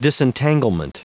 Prononciation du mot disentanglement en anglais (fichier audio)
Prononciation du mot : disentanglement